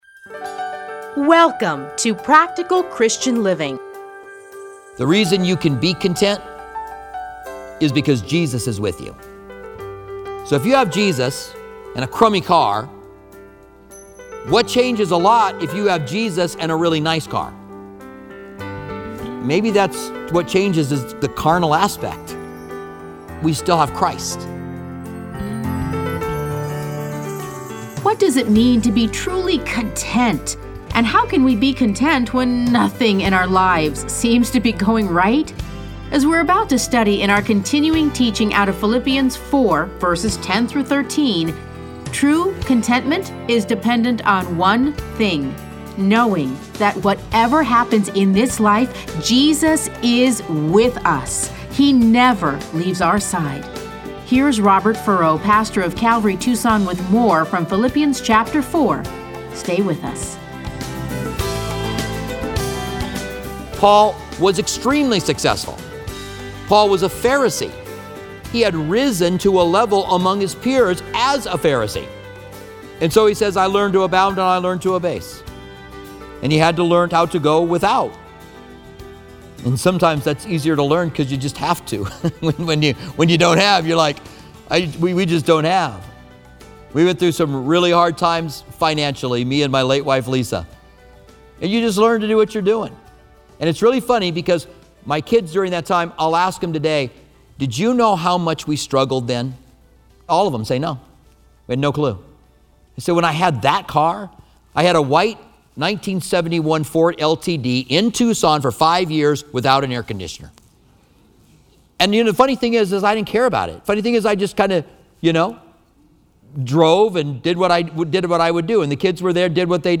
Listen to a teaching from Philippians 4:10-13.